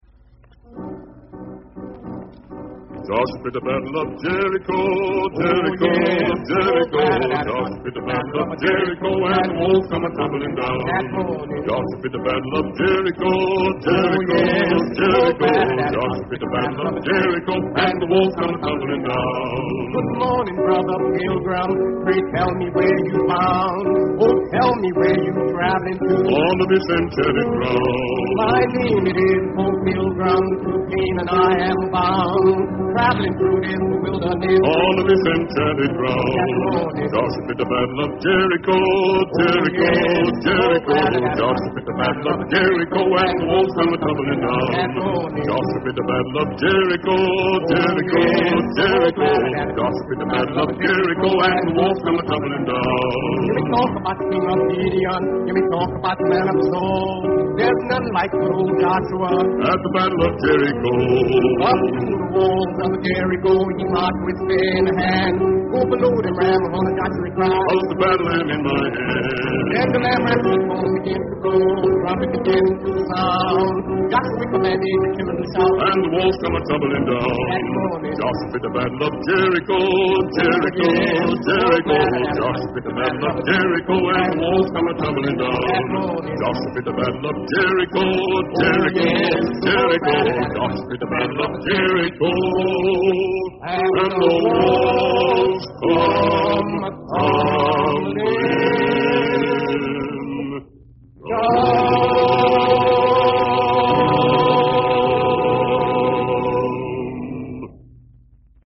Имя Поля Робсона, «борца за права американских негров и большого друга Советского Союза», постоянно упоминалось в прессе, его густой и бархатный бас звучал буквально из каждого репродуктора, а невинные школьные загадки о нём, вроде как из зоологии, типа — «у кого яйца чёрные?», — неизменно входили в репертуар каждого советского пионера.
Их песни мы теперь называем «спиричуэлс» — «духовные песни», потому что в основу их положены какие-то слова, имена и сюжеты, взятые из Библии.
Вот одна из таких песен в исполнении Поля Робсона. Она называется незатейливо и просто: «Joshua Fit the Battle of Jericho», то есть «Джошуа выиграл битву за Иерихон» (скачать):